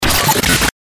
FX-1680-BREAKER
FX-1680-BREAKER.mp3